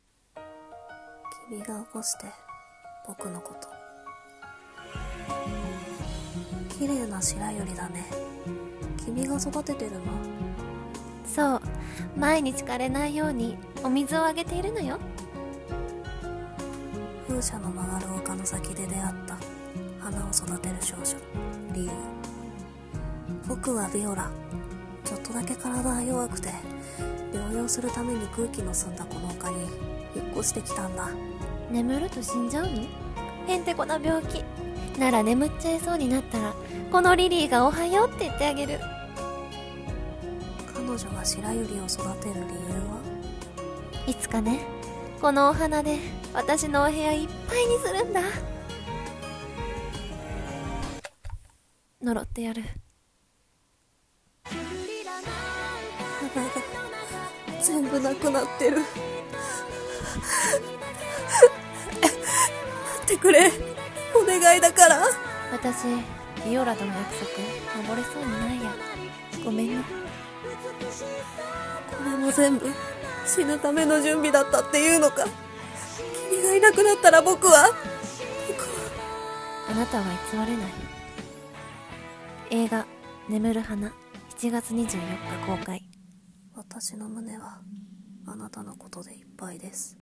CM風声劇「眠る花